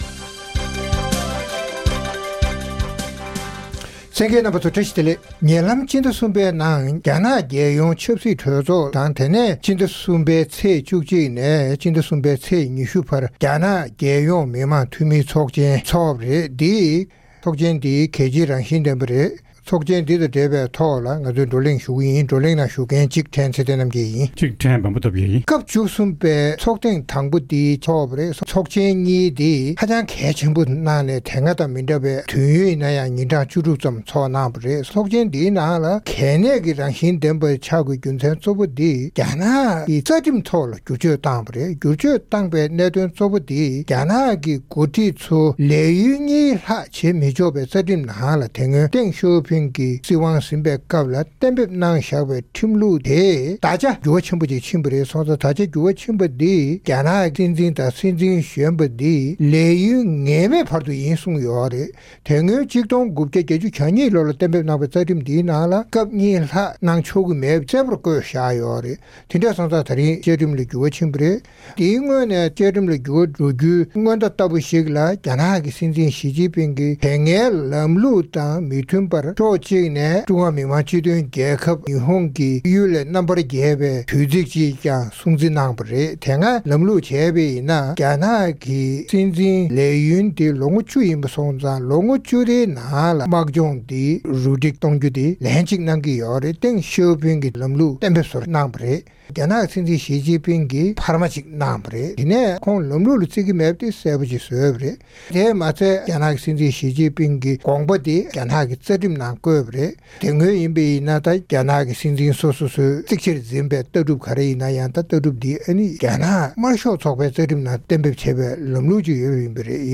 རྩོམ་སྒྲིག་པའི་གླེང་སྟེགས་ཞེས་པའི་ལེ་ཚན་ནང་། ཉེ་ལམ་རྒྱ་ནག་གི་ཚོགས་ཆེན་གཉིས་ཚོགས་ཏེ་རྩ་ཁྲིམས་ལ་བསྐྱར་བཅོས་བཏང་བ་སོགས་གལ་ཆེའི་འགྱུར་འགྲོས་ཁག་གི་སྐོར་རྩོམ་སྒྲིག་འགན་འཛིན་རྣམ་པས་བགྲོ་གླེང་གནང་གསན་རོགས་གནང་།